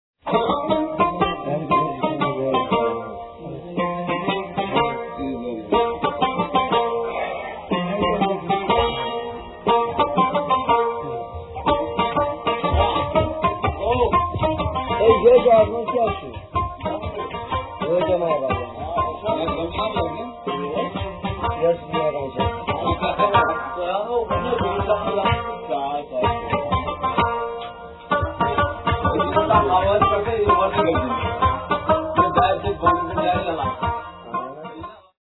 Rebab solo - 1:08